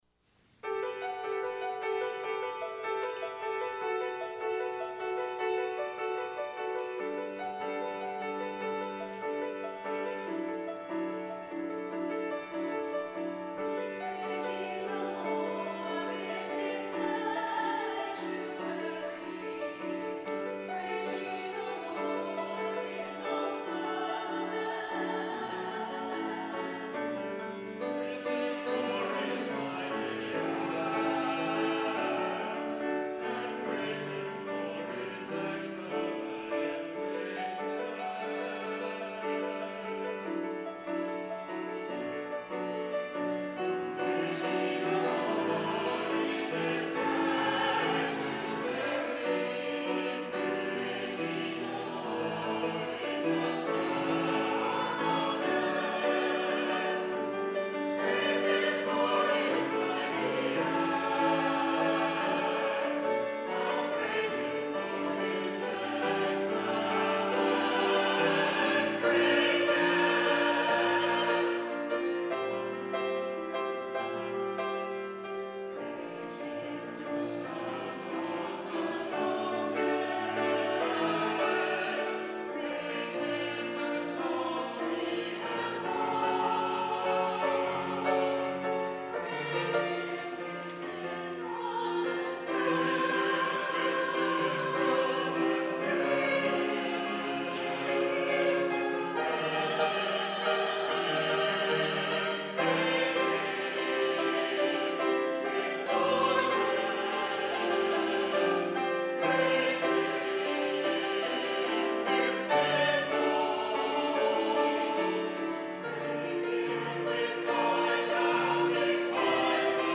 Chancel Choir
piano